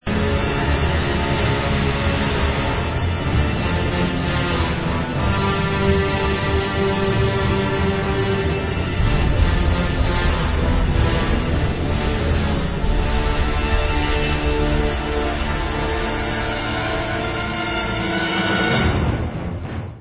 ve studiu Abbey Road